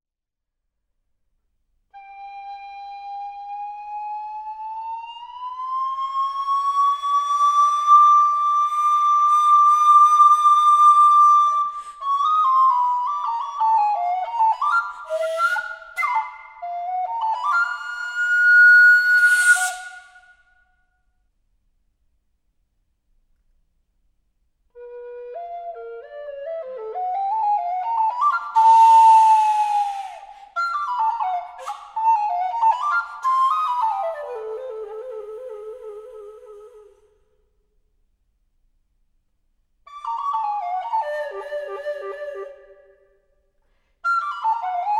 Obsazení: Altblockflöte in G oder F